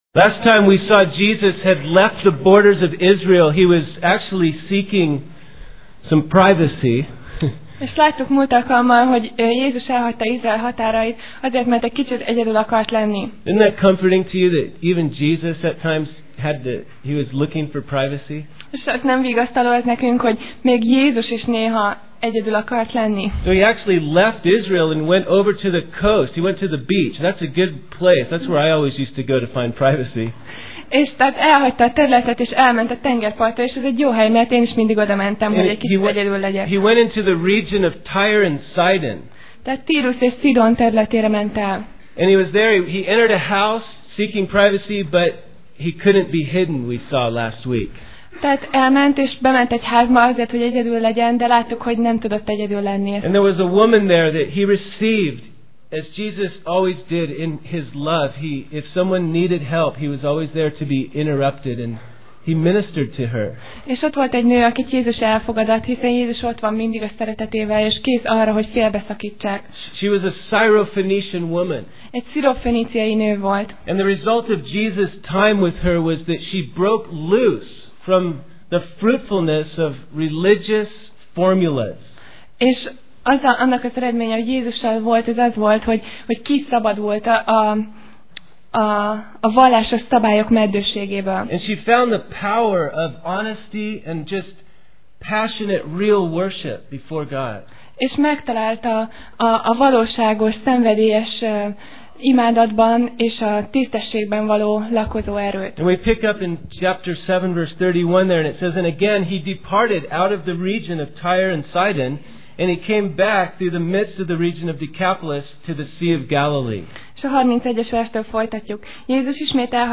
Passage: Márk (Mark) 7:30-37 Alkalom: Vasárnap Reggel